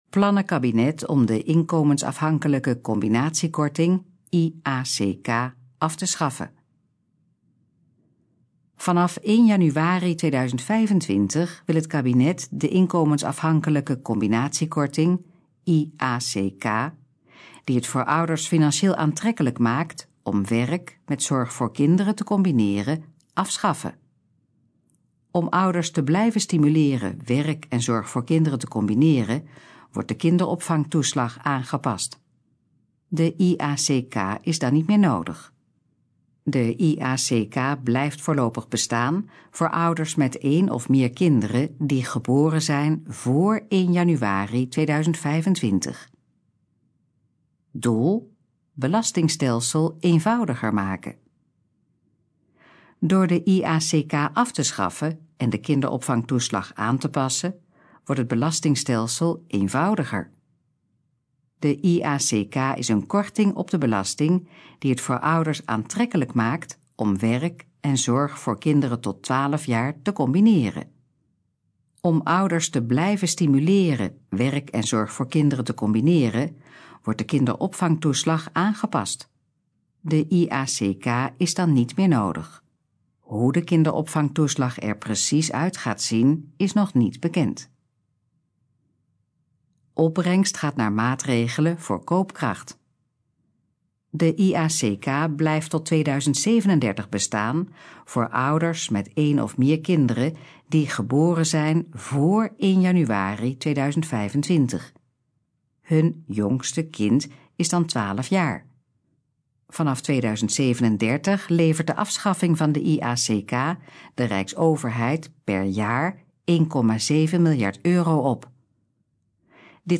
Gesproken versie van Plannen kabinet om de inkomensafhankelijke combinatiekorting (IACK) af te schaffen
In het volgende geluidsfragment hoort u informatie over de plannen om de inkomensafhankelijke combinatiekorting (IACK) af te schaffen. Het fragment is de gesproken versie van de informatie op de pagina Plannen kabinet om de inkomensafhankelijke combinatiekorting (IACK) af te schaffen.